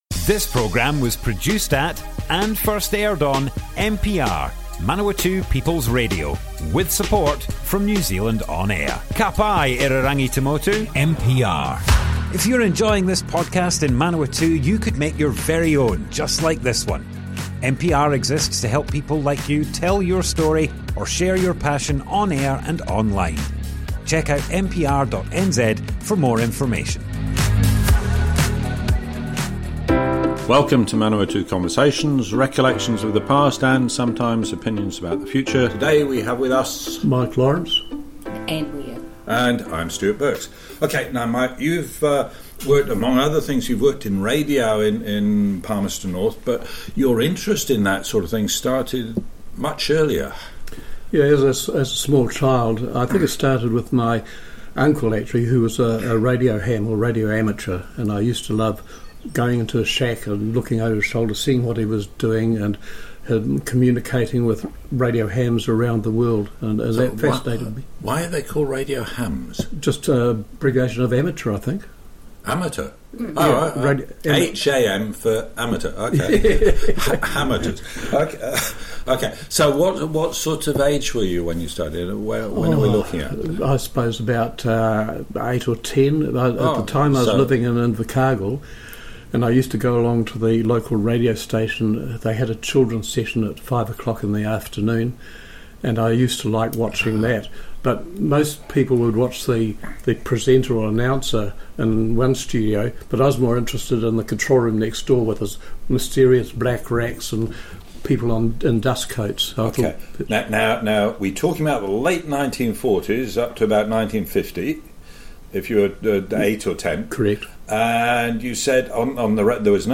Radio in the 1960s and 70s - Manawatu Conversations More Info → Description Broadcast on Manawatu People’s Radio, 26th September 2023.
oral history